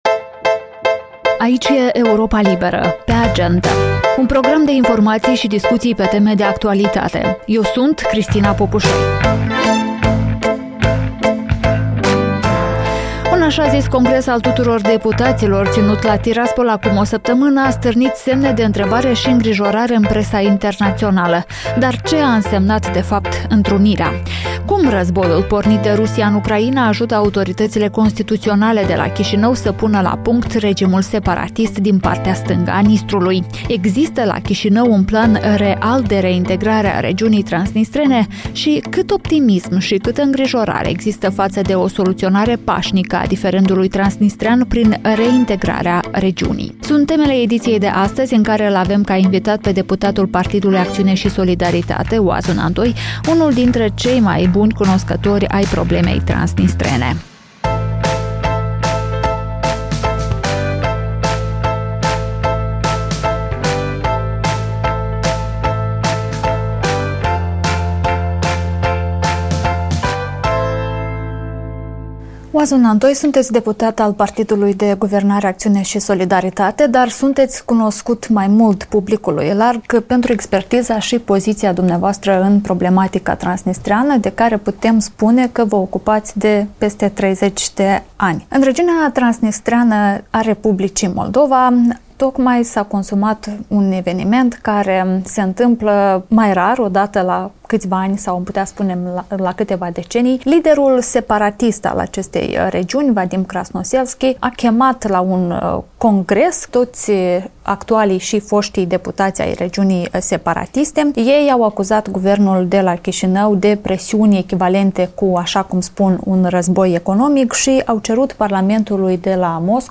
Deputatul Oazu Nantoi vorbește în cel mai recent podcast „Pe Agendă” de la Europa Liberă despre efectele așa-zisului congres de la Tiraspol, cum a schimbat războiul din Ucraina modul de aplicare a pârghiilor pe care le au autoritățile constituționale de la Chișinău asupra Tiraspolului.